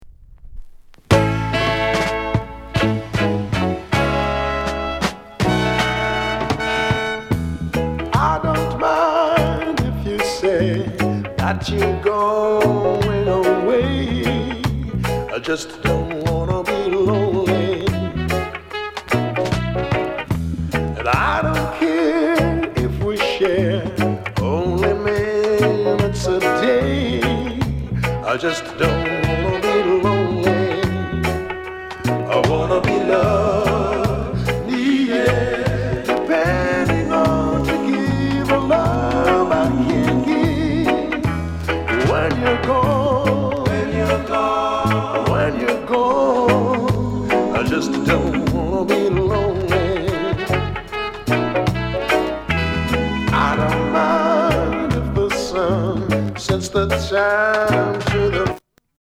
SOUND CONDITION VG
NICE SOULFUL COVER